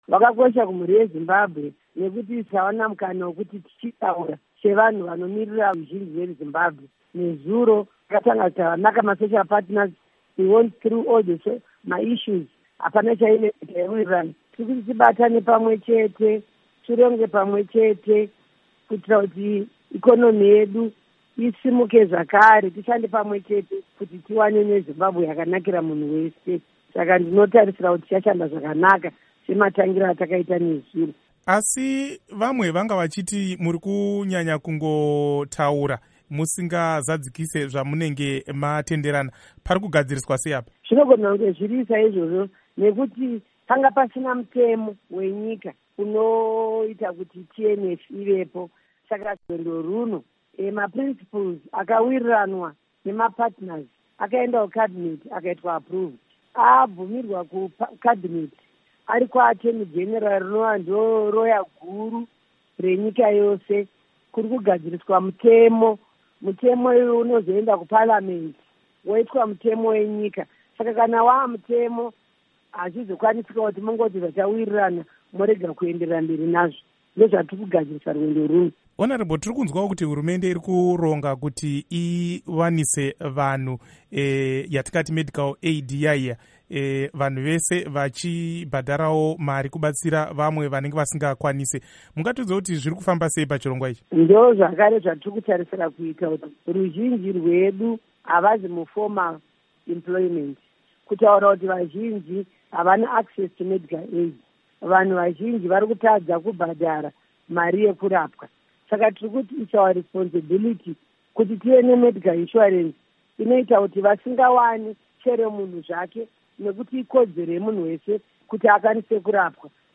Hurukuro naAmai Priscah Mupfumira